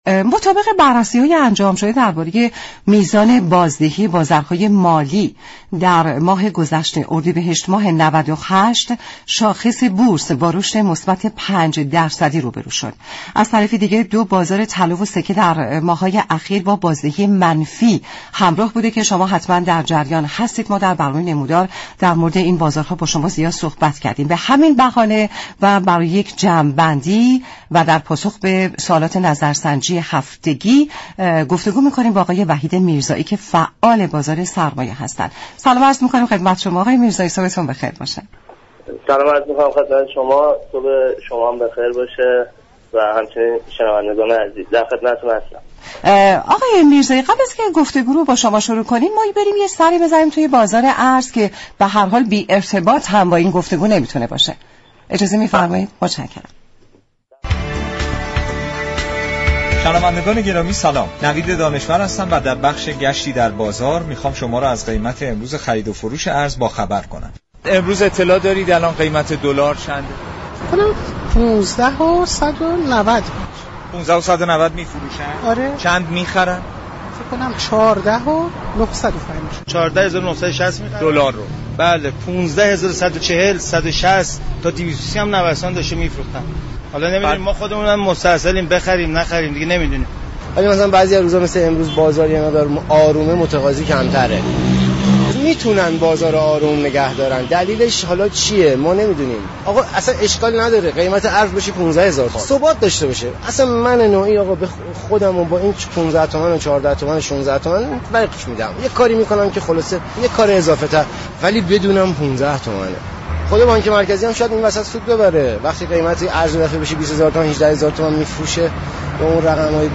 فعال بازار سرمایه در گفت و گو با برنامه "نمودار"